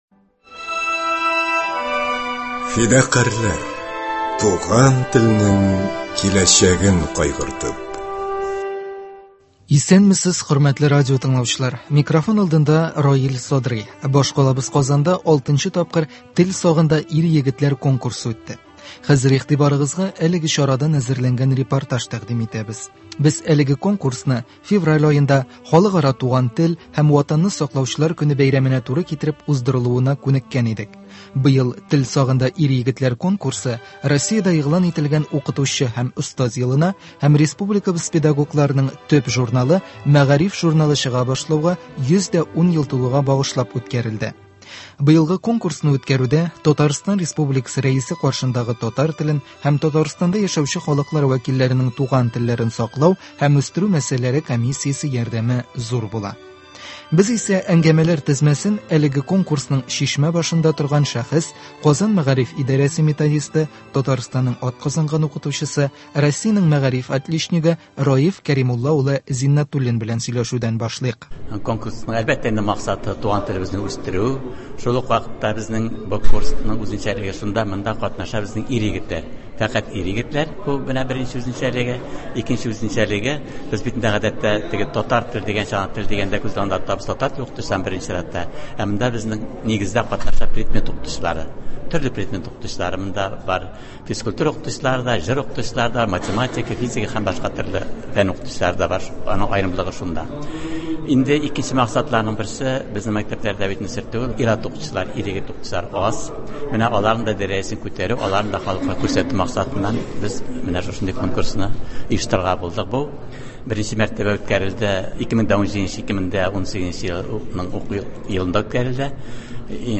Башкалабыз Казанда 6 нчы тапкыр “Тел сагында – ир-егетләр” конкурсы үтте. Хәзер игътибарыгызга әлеге чарадан әзерләнгән репортаж тәкъдим итәбез.